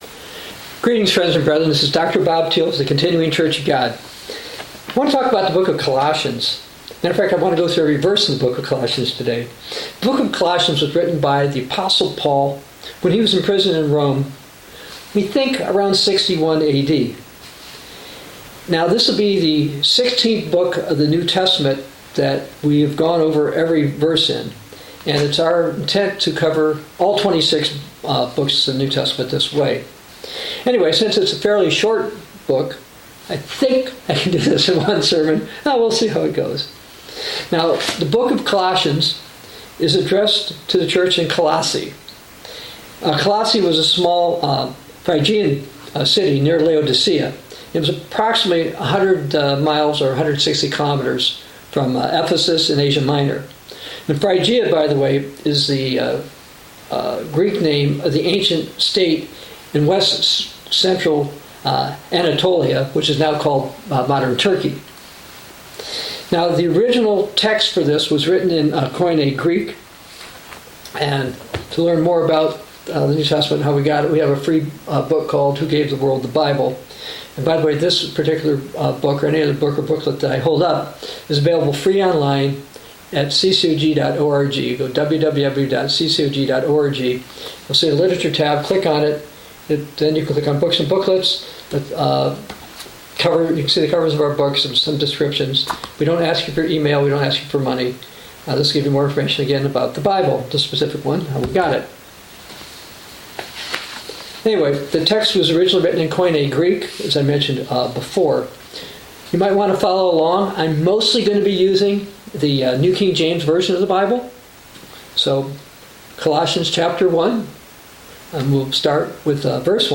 Talk Show Episode, Audio Podcast, Bible News Prophecy and Book of Colossians on , show guests , about Book of Colossians, categorized as Health & Lifestyle,History,Love & Relationships,Philosophy,Psychology,Christianity,Inspirational,Motivational,Society and Culture